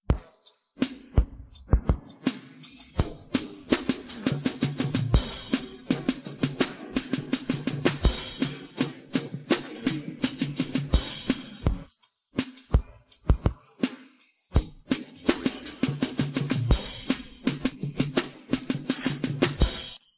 Dazed X Amazed Drum Loop.wav